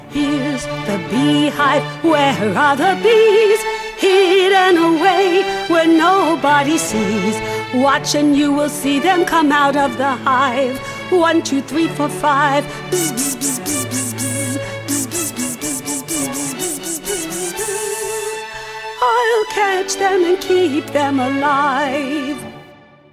Genre: Children's Music.